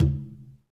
Tumba-HitN_v2_rr2_Sum.wav